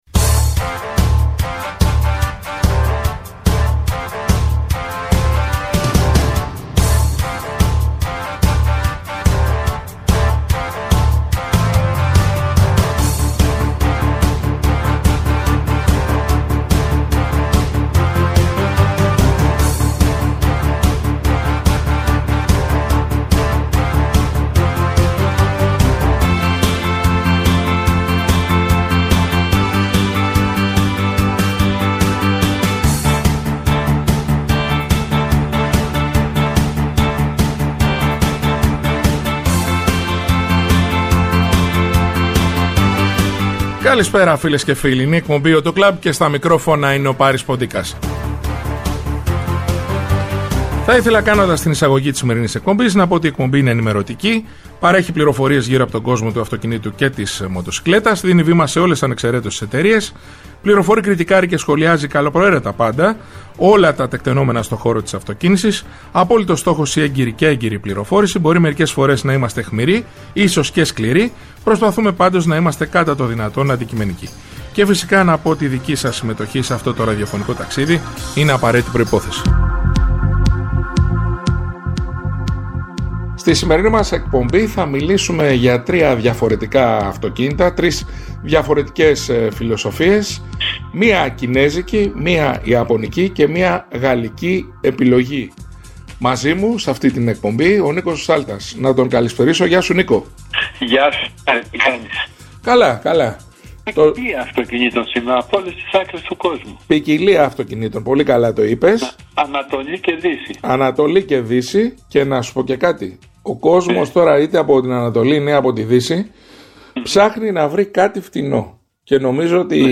Η εκπομπή «AUTO CLUB» είναι ενημερωτική, παρέχει πληροφορίες γύρω από τον κόσμο του αυτοκινήτου και της μοτοσικλέτας, δίνει βήμα σε όλες ανεξαιρέτως τις εταιρείες, φιλοξενεί στο στούντιο ή τηλεφωνικά στελέχη της αγοράς, δημοσιογράφους αλλά και ανθρώπους του χώρου. Κριτικάρει και σχολιάζει καλοπροαίρετα πάντα όλα τα τεκταινόμενα στο χώρο της αυτοκίνησης, με απόλυτο στόχο την έγκαιρη και έγκυρη πληροφόρηση για τους ακροατές, με «όπλο» την καλή μουσική και το χιούμορ.